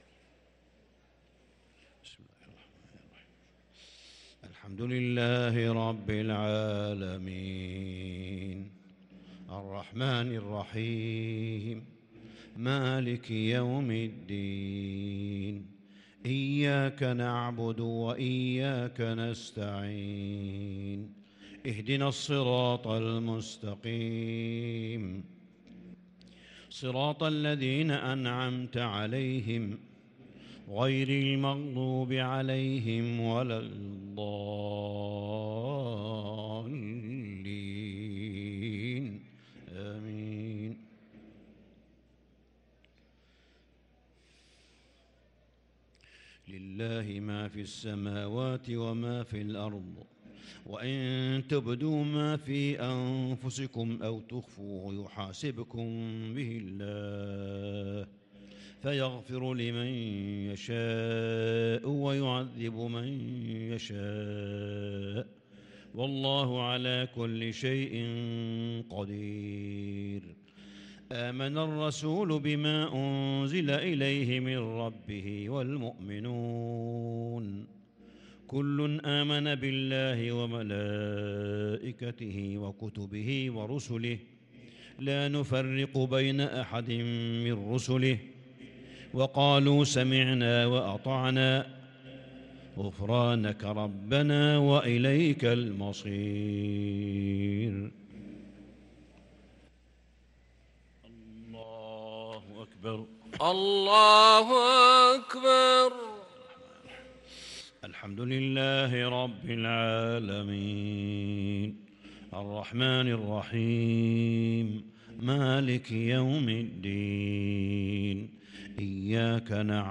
صلاة العشاء للقارئ صالح بن حميد 29 شعبان 1443 هـ